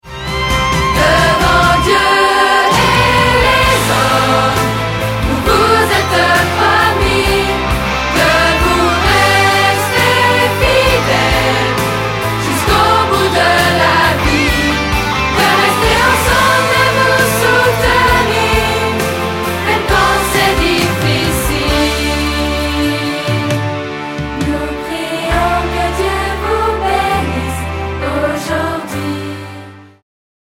comédie musicale